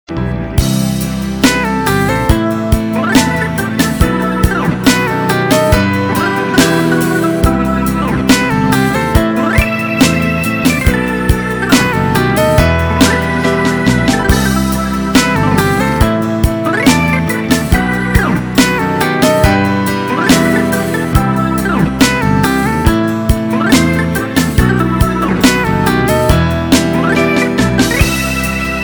лирика
инструментальные
Лирическая инструментальная композиция